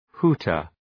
{‘hu:tər}